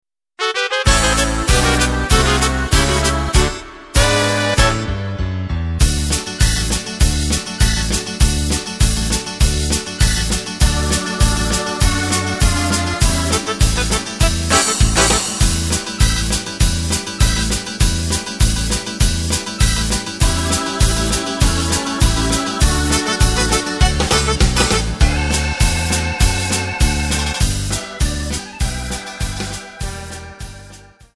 Takt:          4/4
Tempo:         194.00
Tonart:            D
Dixie aus dem Jahr 1968!
Playback mp3 Demo